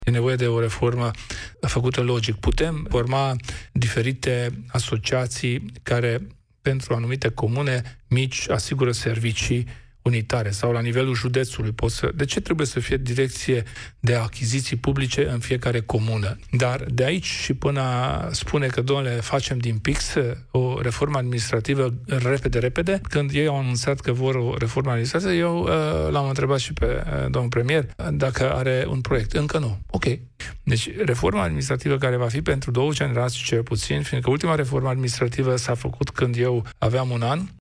Reforma administrativă nu trebuie făcută din pix și nici în grabă – spune la emisiunea „Piața Victoriei”, la Europa FM, președintele UDMR, Kelemen Hunor.